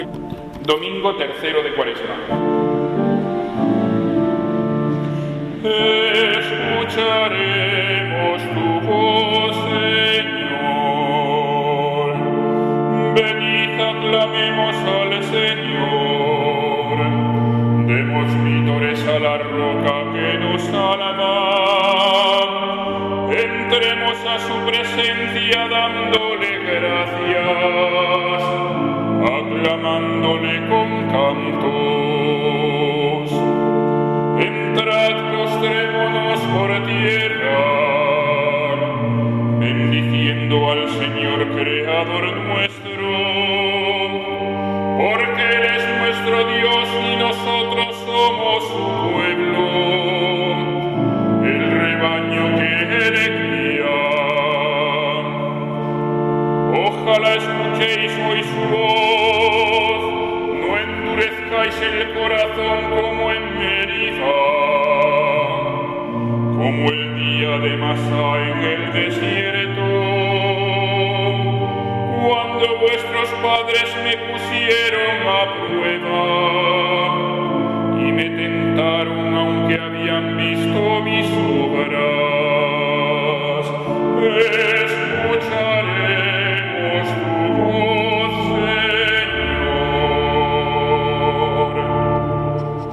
Salmo Responsorial [1.453 KB]